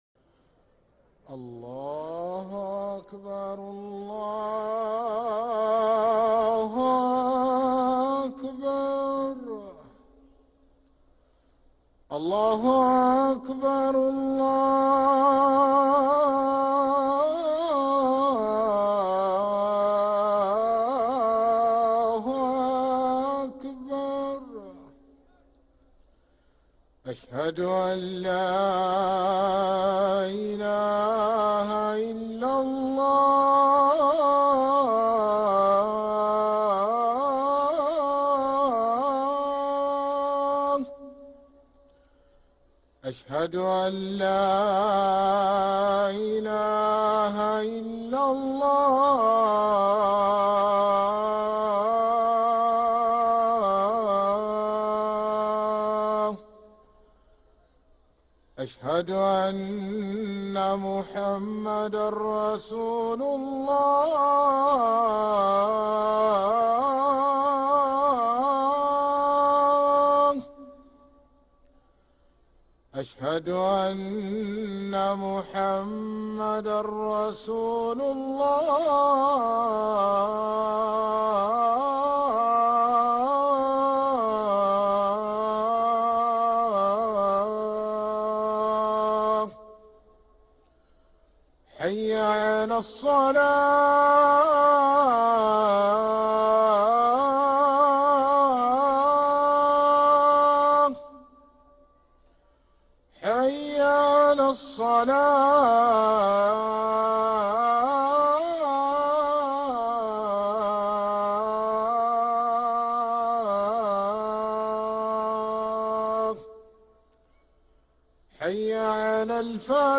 أذان الظهر للمؤذن سعيد فلاته الاثنين 4 ربيع الثاني 1446هـ > ١٤٤٦ 🕋 > ركن الأذان 🕋 > المزيد - تلاوات الحرمين